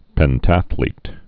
(pĕn-tăthlēt)